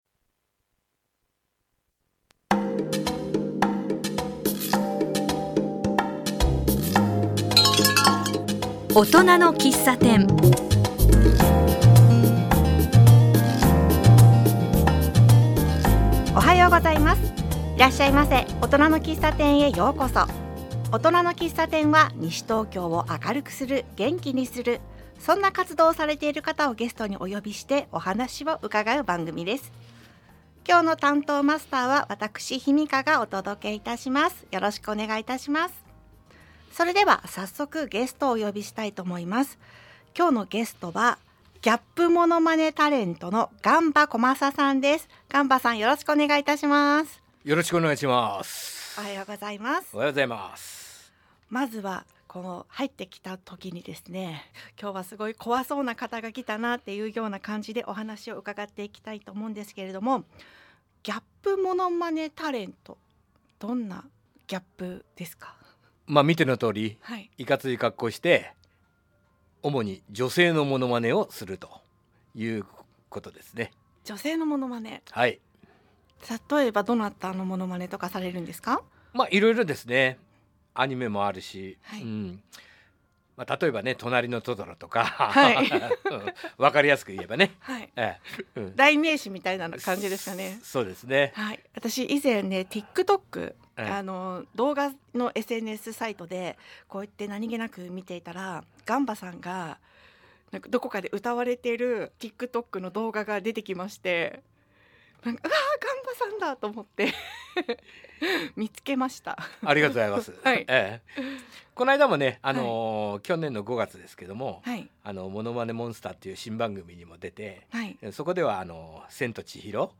強面から繰り出される美しい声のギャップ。